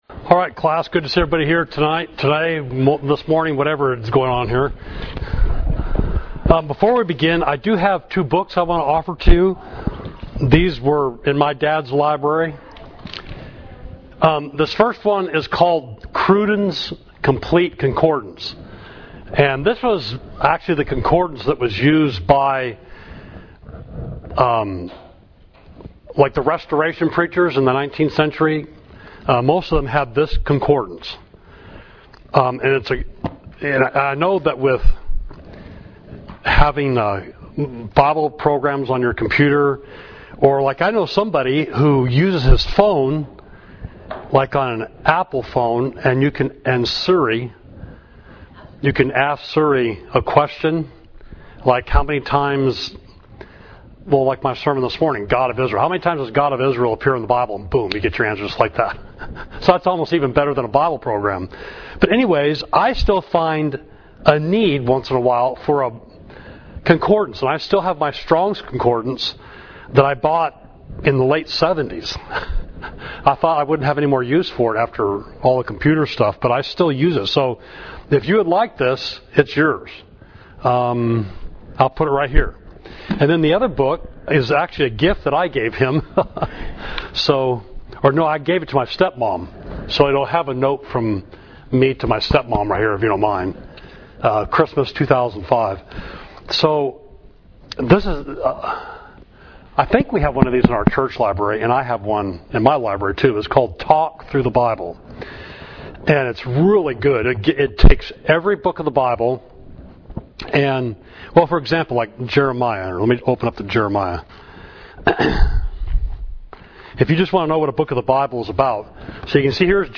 Class: Prophecies Against Nations, Jeremiah 46–51